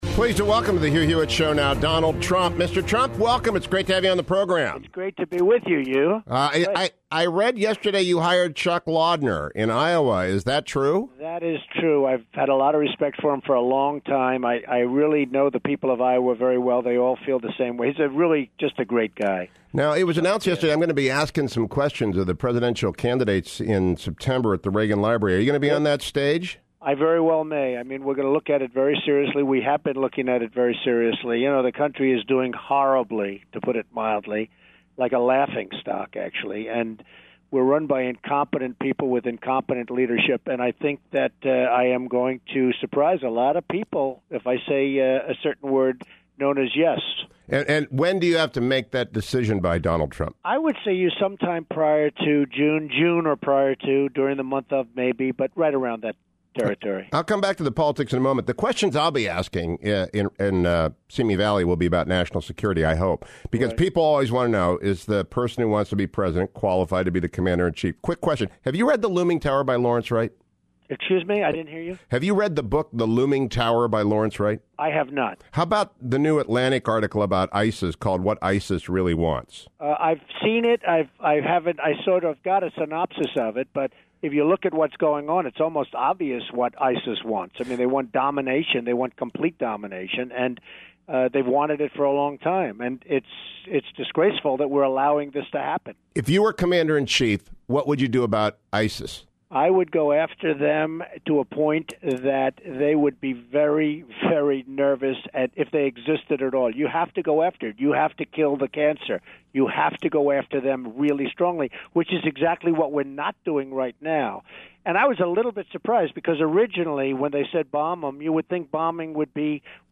Donald Trump joined me Wednesday to talk about whether or not he’ll be on the stage when I am asking questions in the fall: